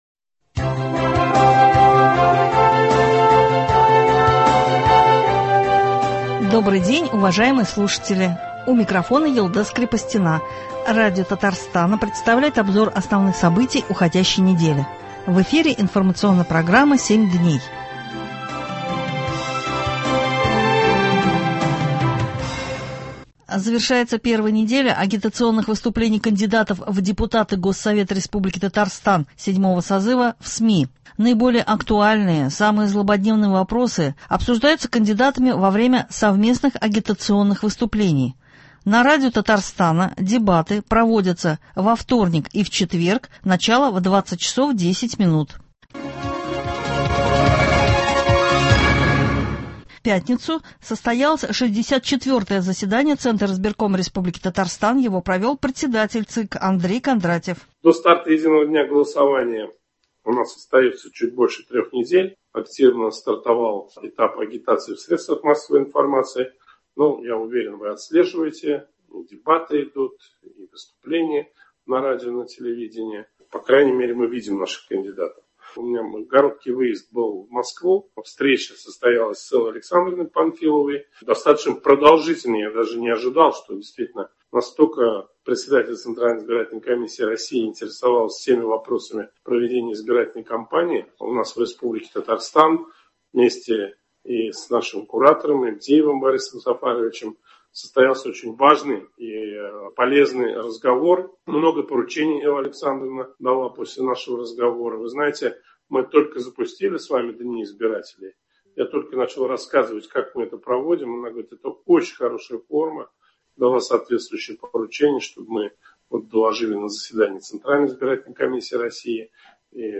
Обзор событий недели.